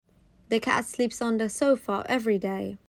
تلفظ با سرعت‌های مختلف